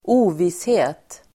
Uttal: [²'o:vishe:t]